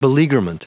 Prononciation du mot beleaguerment en anglais (fichier audio)
Prononciation du mot : beleaguerment